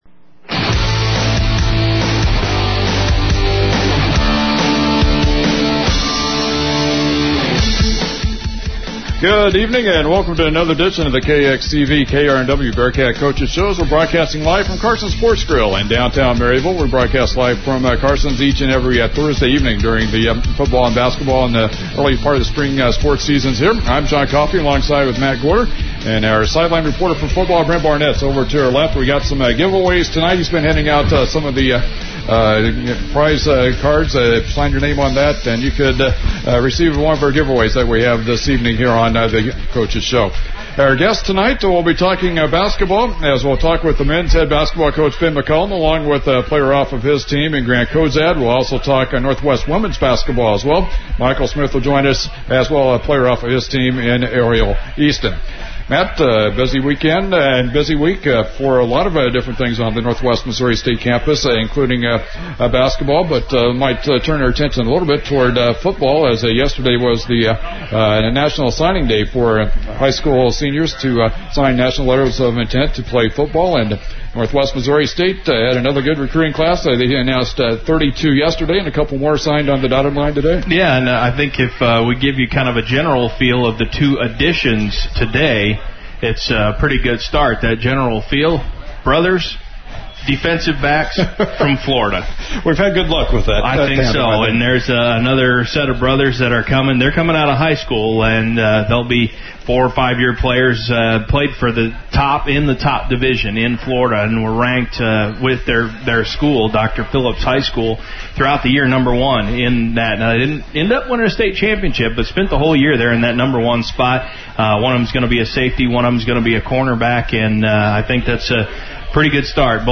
KXCV-KRNW is the public radio service of Northwest Missouri State University, Maryville.
Local Sports